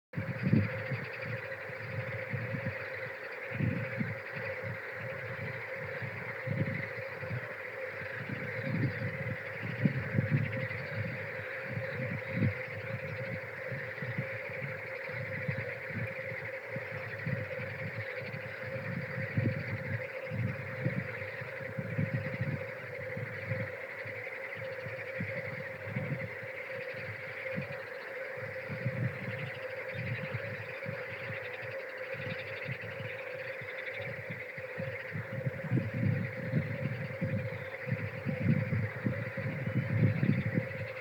Újra vöröshasú unka kórusoktól hangos a Hortobágy
Nászidőszakban a hímek belső hanghólyagjuk segítségével a jól ismert „unk-unk” strófákat ismételgetik, melyről a faj a magyar nevét is kapta.
A monoton, mély, de egyben nyugalmat sugárzó kórus dallama a tavaszi pusztavilág elválaszthatatlan részét alkotják.